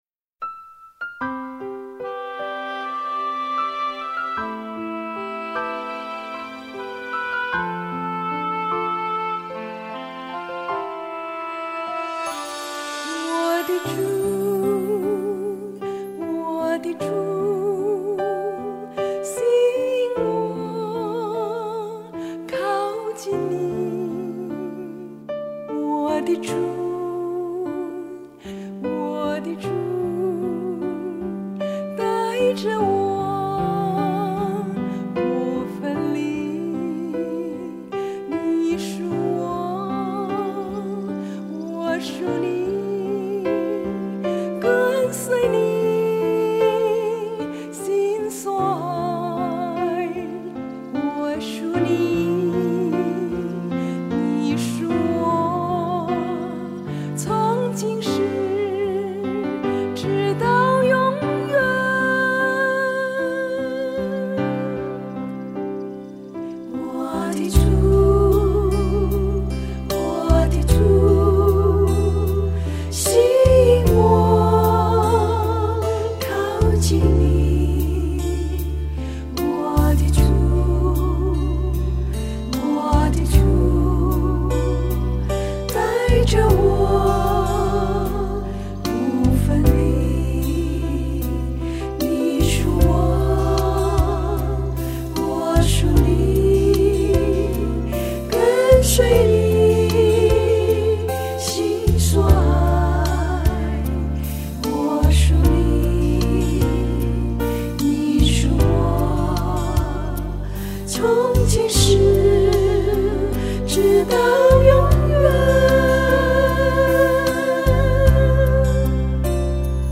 C調 4/4